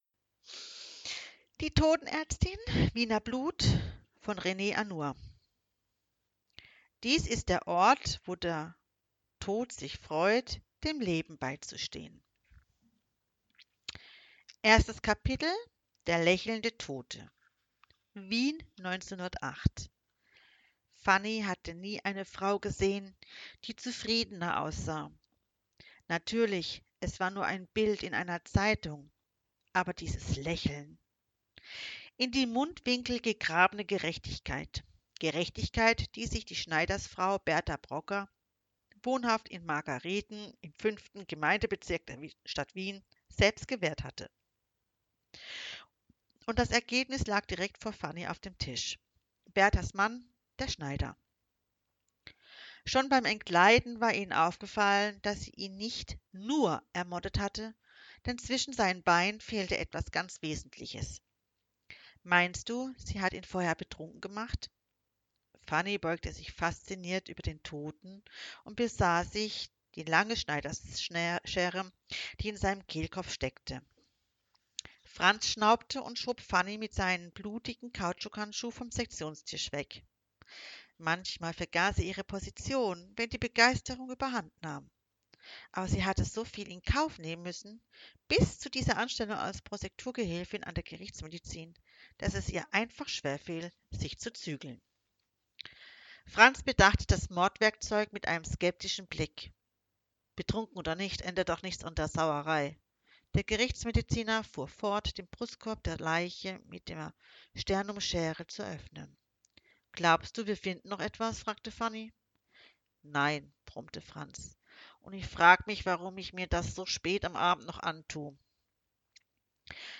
Hörprobe: Die Totenärztin – Wiener Blut von Rene Anour